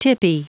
Prononciation du mot tippy en anglais (fichier audio)
Prononciation du mot : tippy